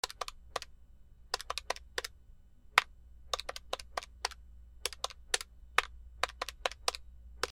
電卓
/ M｜他分類 / L01 ｜小道具 / 文房具・工作道具
原音あり C414BXL2